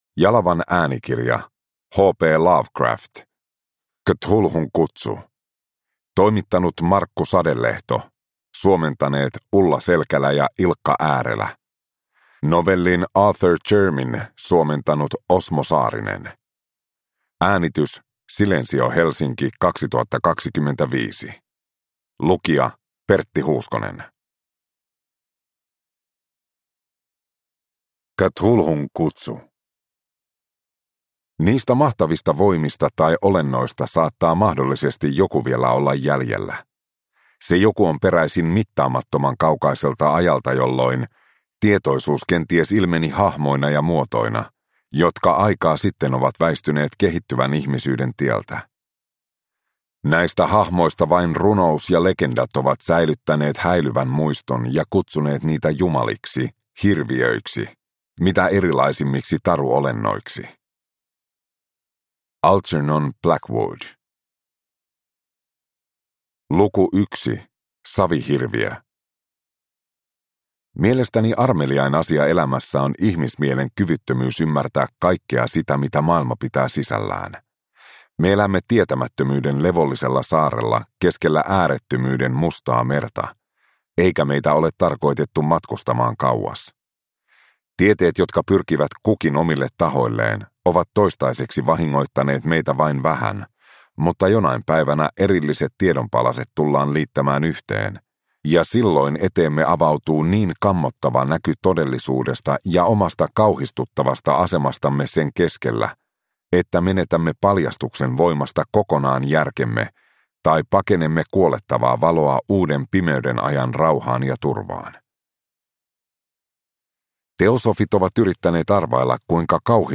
Cthulhun kutsu – Ljudbok